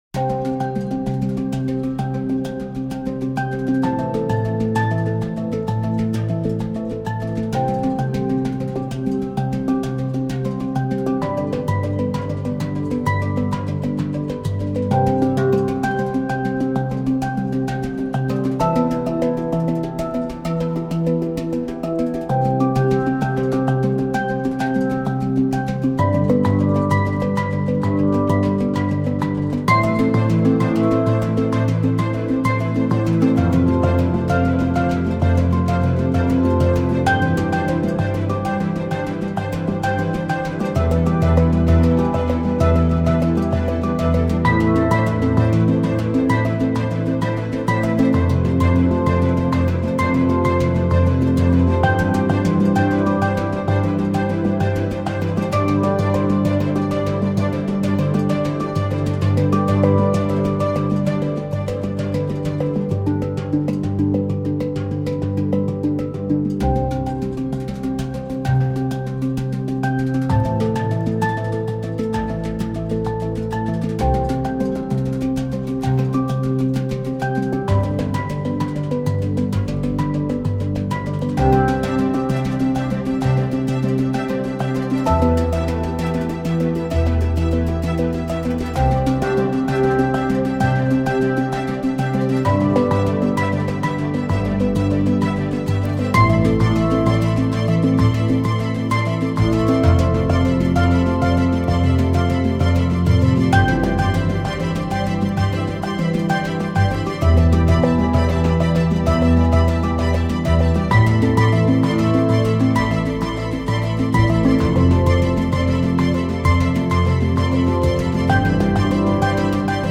reduced without melody